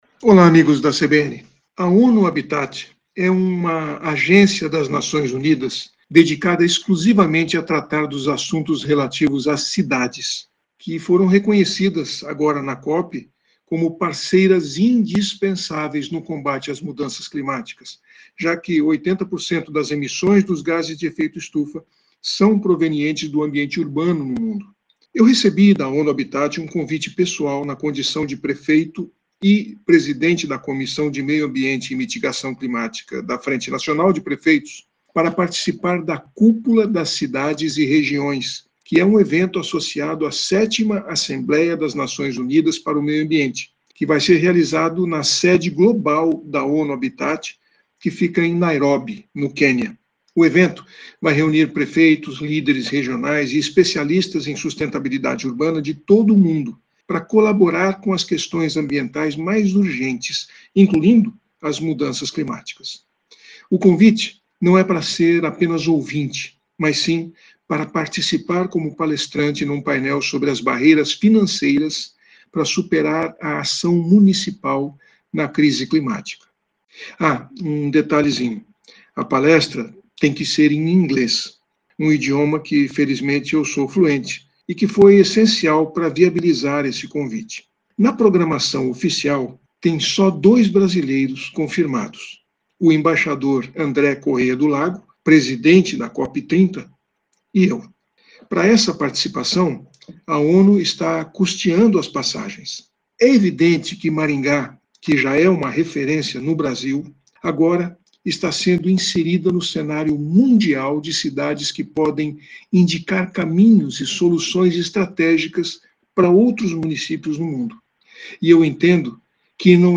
CBN Cidadania e Sustentabilidade, com Silvio Barros, fala sobre atitudes sustentáveis feitos por instituições e pessoas.